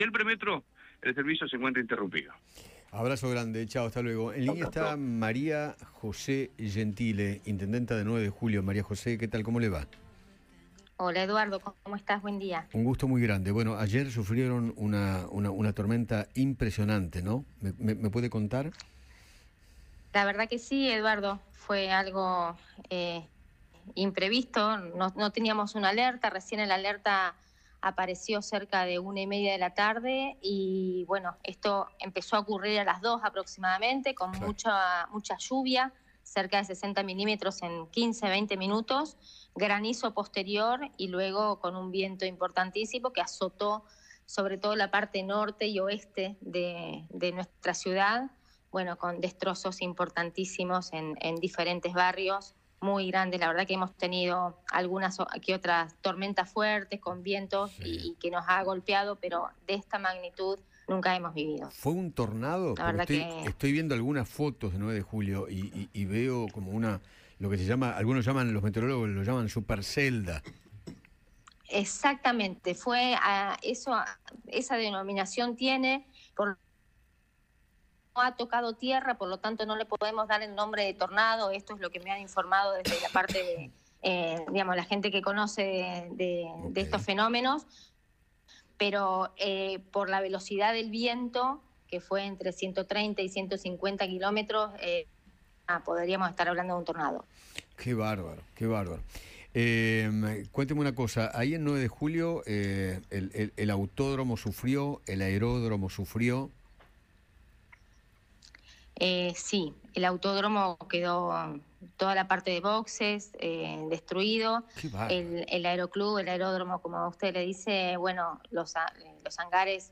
Eduardo Feinmann conversó con María José Gentile, intendenta de 9 de Julio, sobre los destrozos que sufrió la ciudad por el temporal.